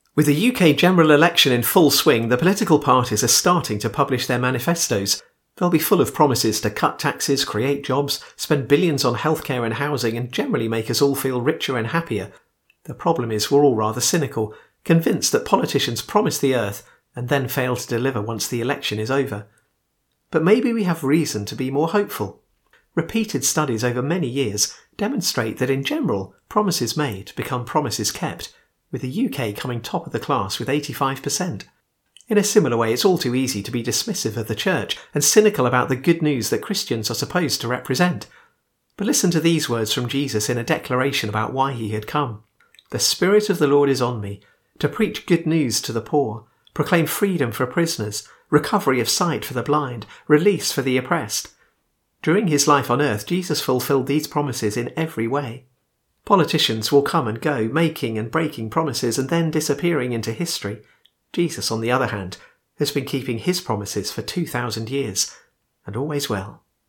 Short Thought gives us inspiring and thoughtful 60 second messages, which are updated every week.